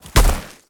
Sfx_creature_snowstalker_walk_05.ogg